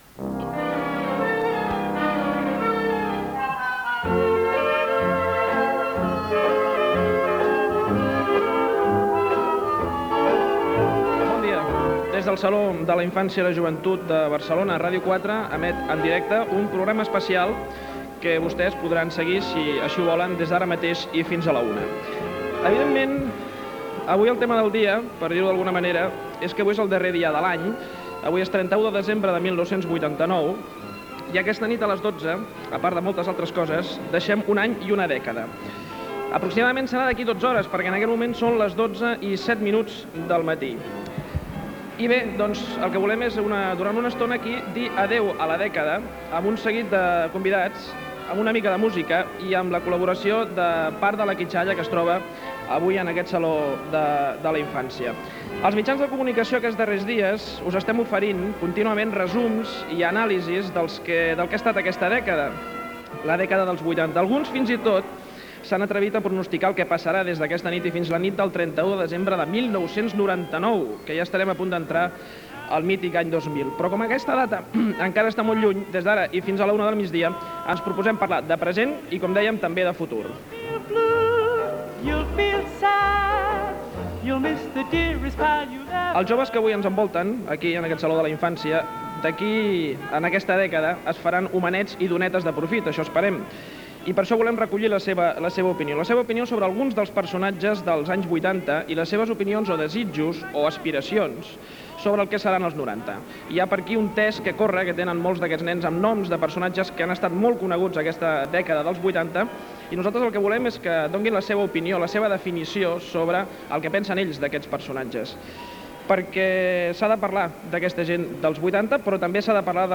Espai fet des del Saló de la Infància de Barcelona, amb una entrevista al cantant Gerard Quintana de Sopa de Cabra i les opinions dels adolescents sobre temes d'actualitat del moment
Info-entreteniment